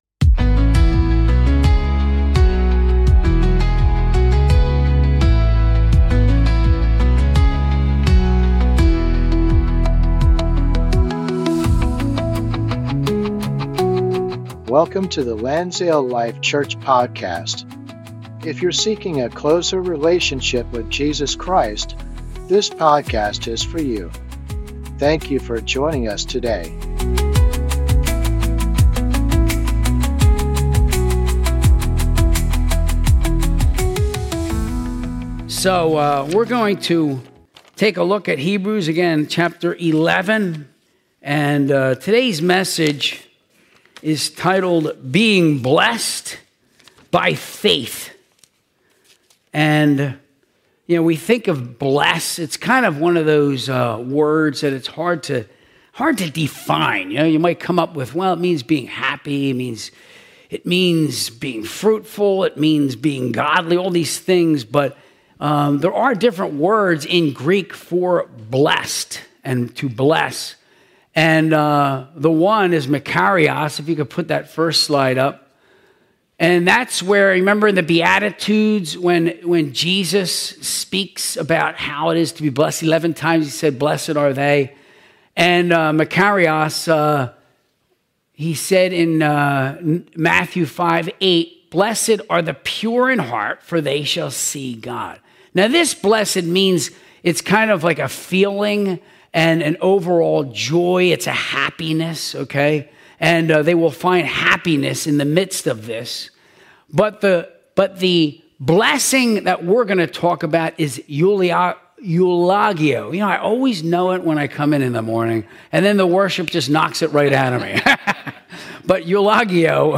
Sunday Service - 2025-11-30